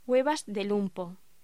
Locución: Huevas de lumpo
voz